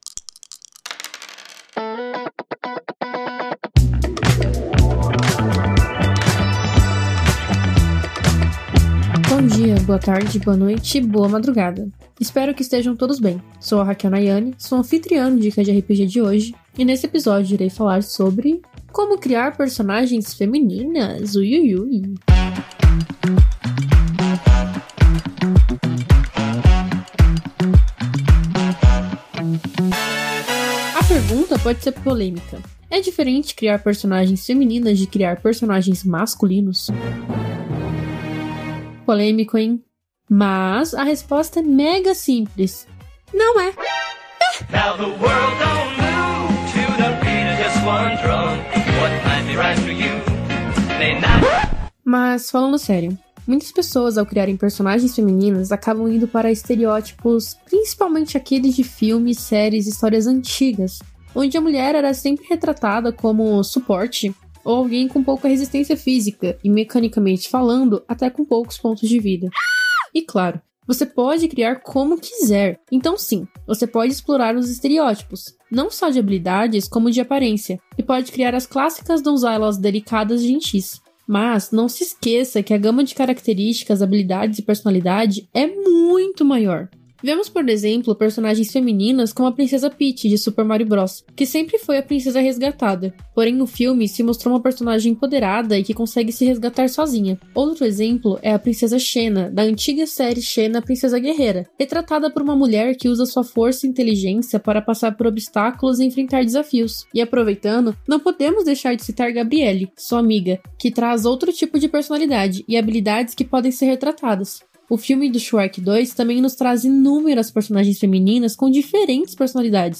O Dicas de RPG é um podcast semanal no formato de pílula que todo domingo vai chegar no seu feed.